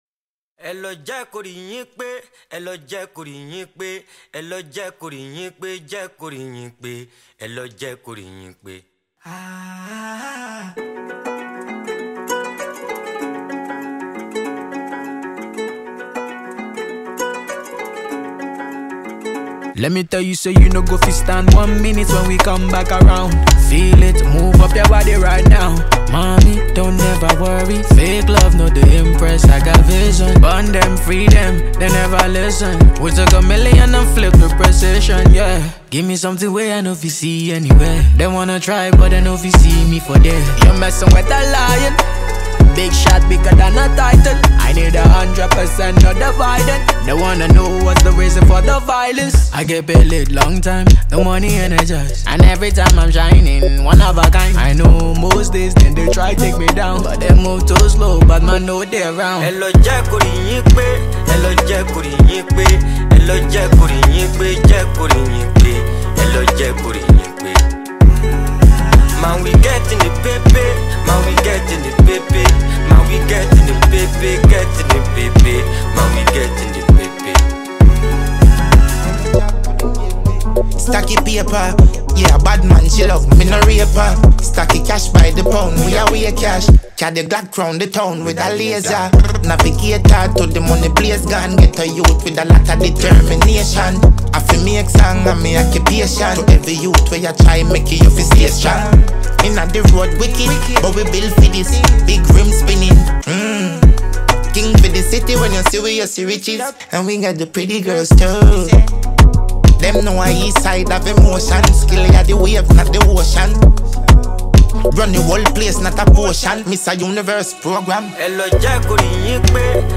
Afro pop Afrobeats